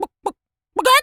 chicken_cluck_to_scream_01.wav